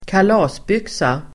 Uttal: [²kal'a:sbyk:sa]